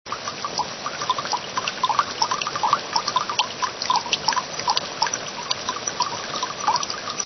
На этой странице собраны звуки весенней капели – мелодичное журчание талой воды, звонкие переливы падающих капель с сосулек.
Шепот весенней капели